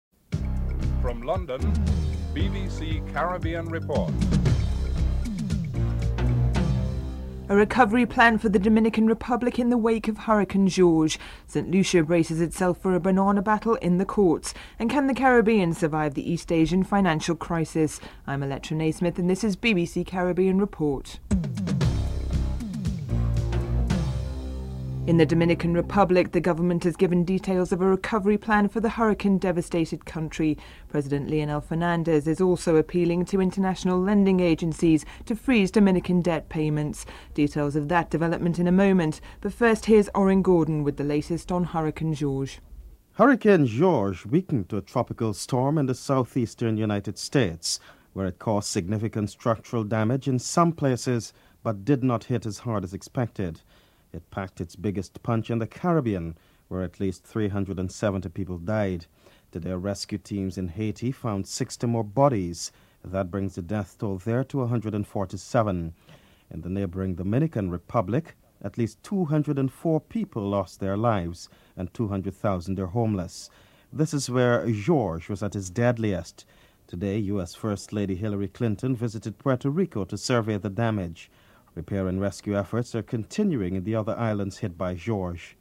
The islands of the Caribbean continue to be vulnerable to hurricanes which cause tremendous damage over the years. British Tycoon Richard Branson and Premier Vance Amory are interviewed discuss whether the region has learned any new lessons.